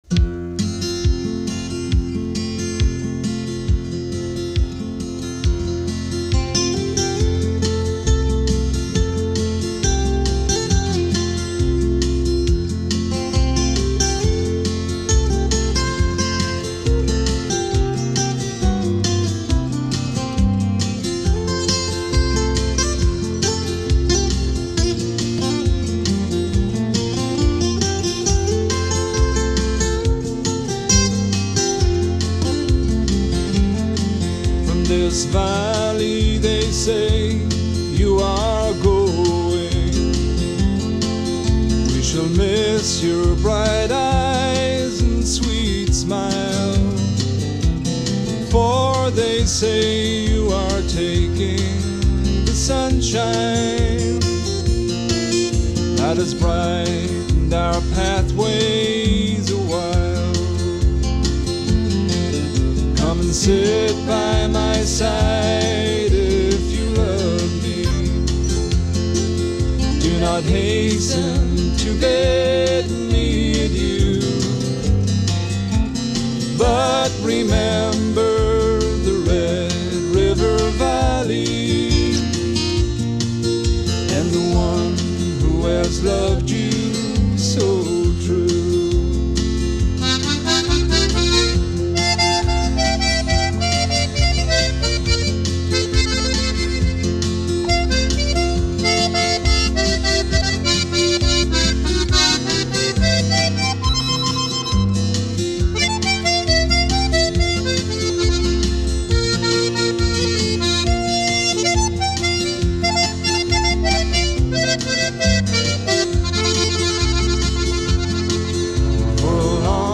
Recorded in Beau St.
American Bluegrass cross-over into country folk.